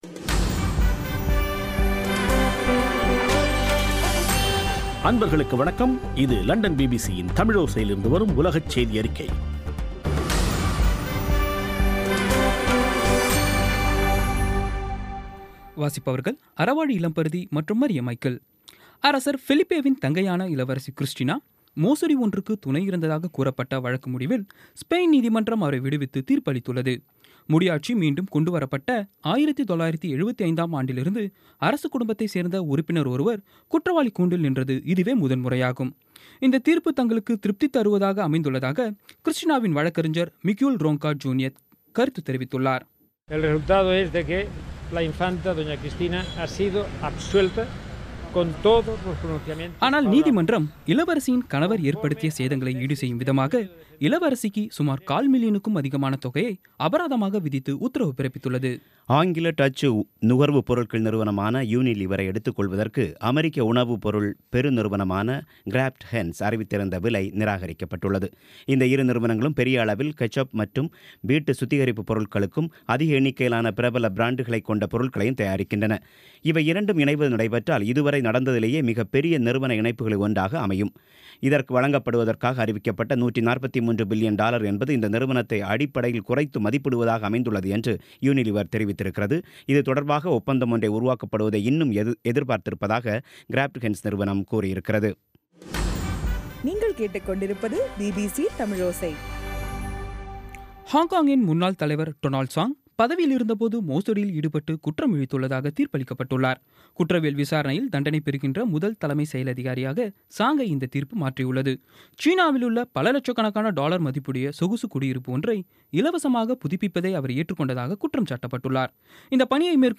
பிபிசி தமிழோசை செய்தியறிக்கை (17/02/17)